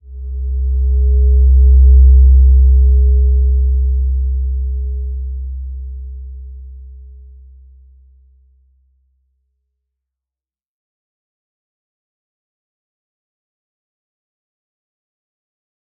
Slow-Distant-Chime-C2-mf.wav